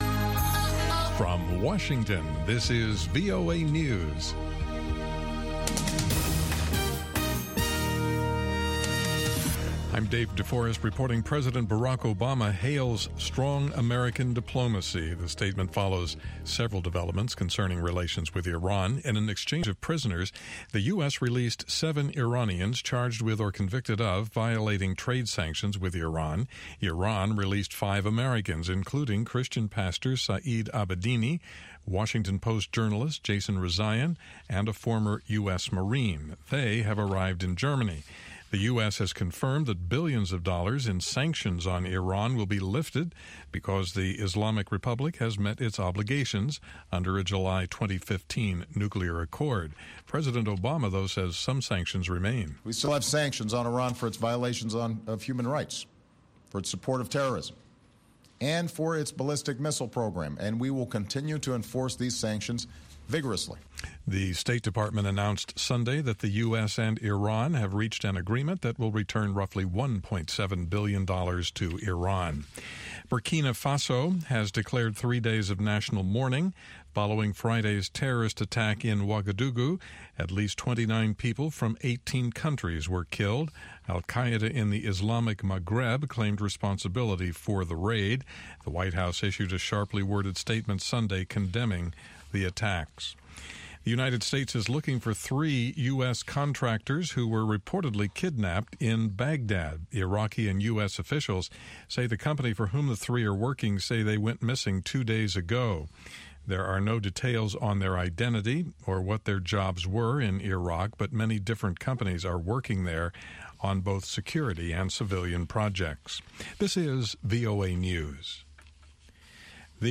N’dombolo to Benga to African Hip Hop
the best mix of pan-African music